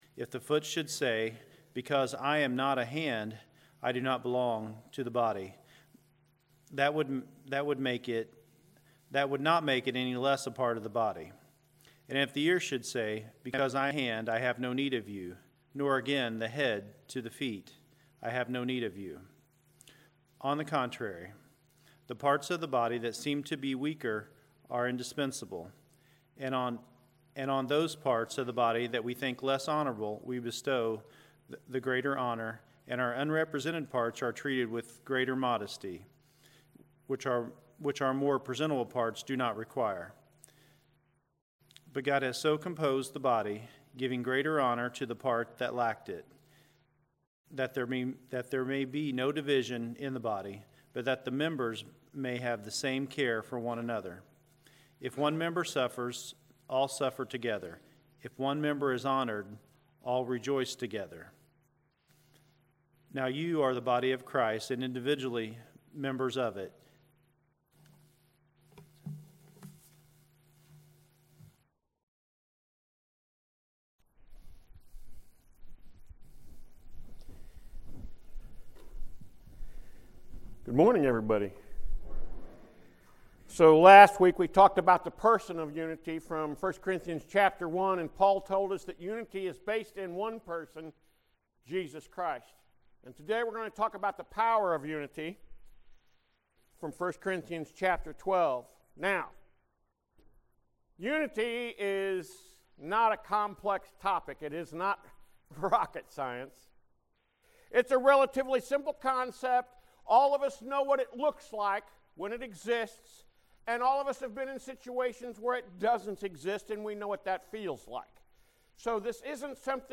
Sermons | Central Church of Christ